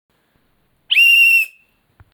penalty Meme Sound Effect
Category: Sports Soundboard